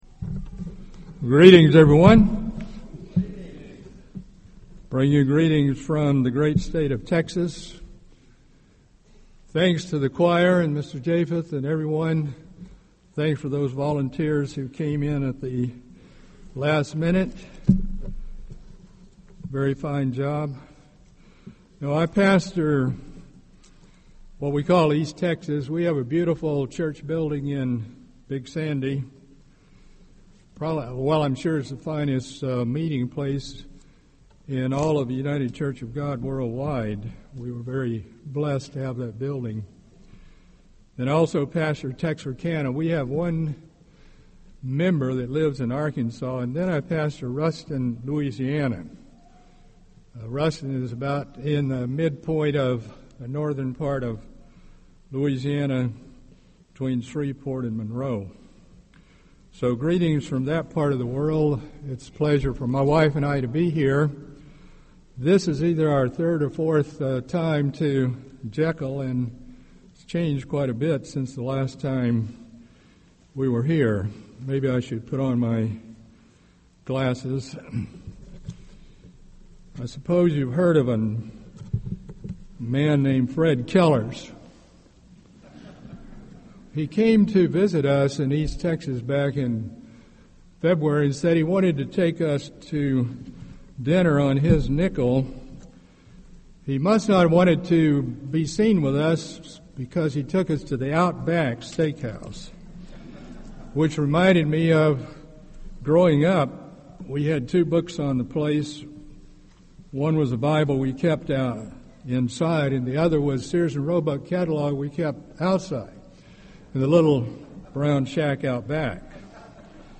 This sermon was given at the Jekyll Island, Georgia 2014 Feast site.